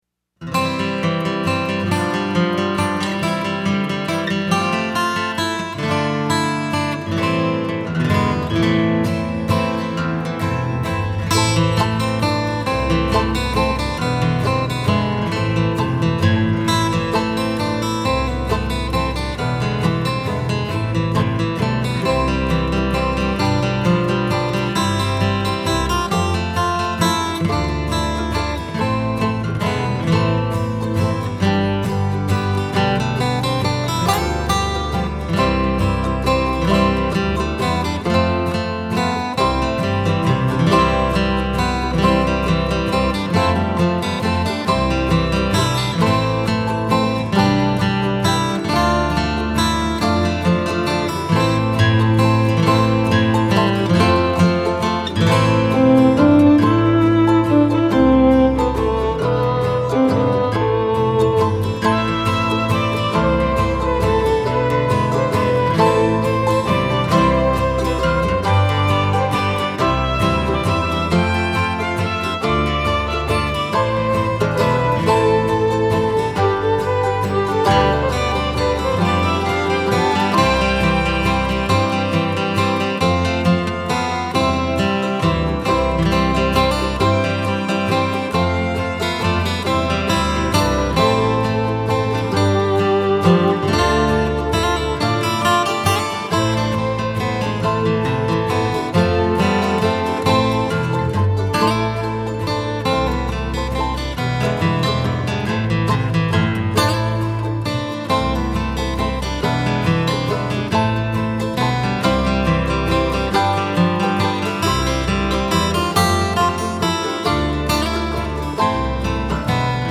DIGITAL SHEET MUSIC - GUITAR SOLO
• Christmas, Bluegrass, Flatpicking, Guitar Solo